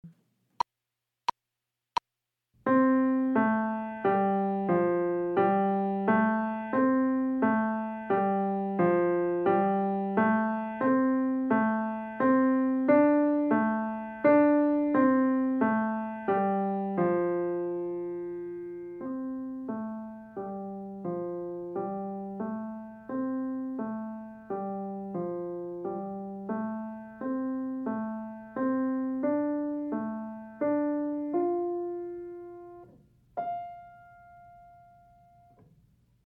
연습